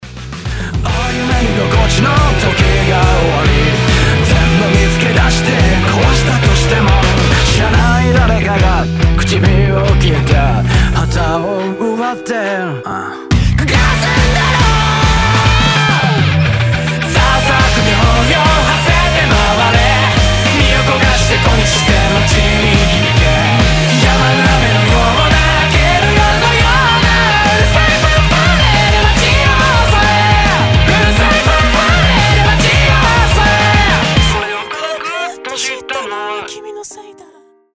• Качество: 320, Stereo
японские
j-rock